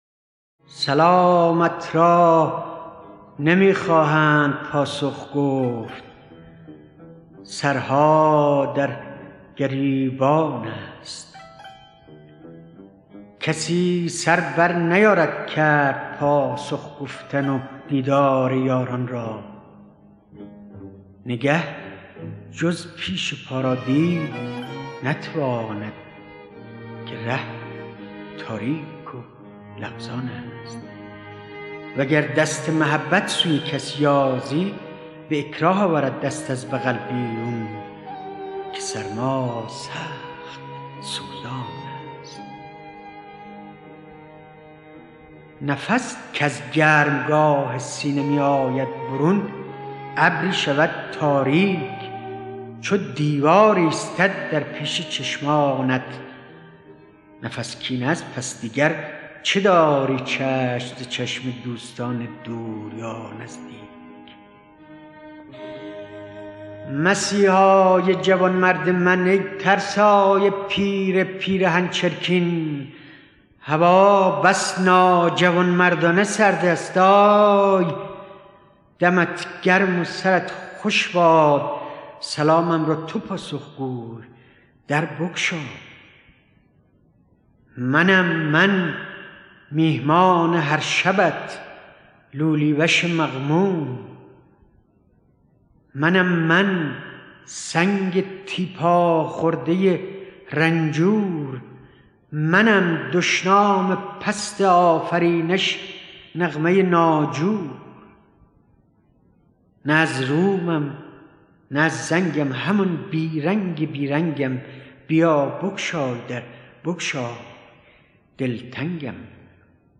دکلمه مهدي اخوان ثالث
گوینده :   [مهدي اخـوان ثالث]
آهنگساز :   مجید درخشانی